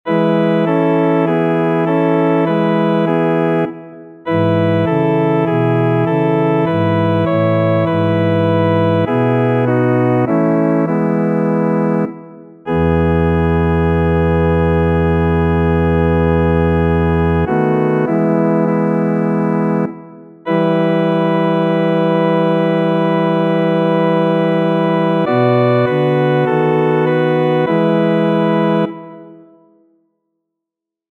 Number of voices: 1v Voicings: S or T Genre: Sacred, Responsory
Language: Czech Instruments: Organ